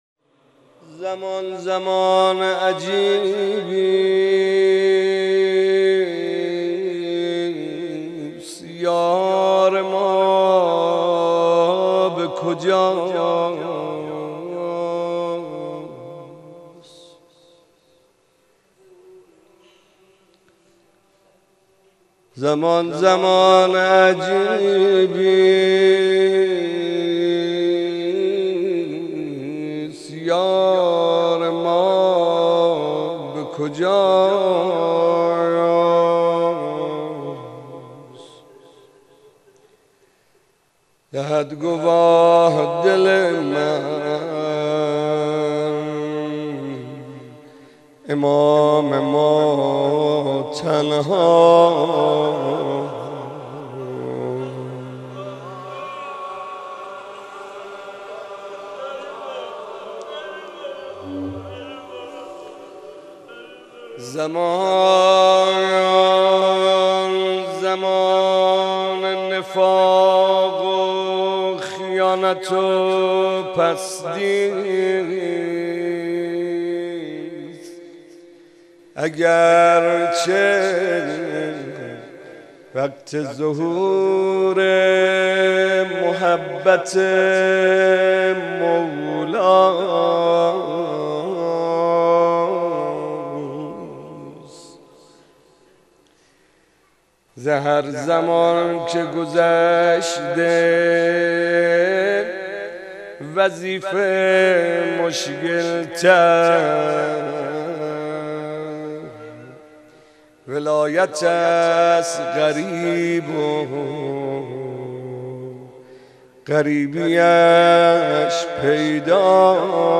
مناسبت : وفات حضرت زینب سلام‌الله‌علیها
مداح : محمدرضا طاهری قالب : روضه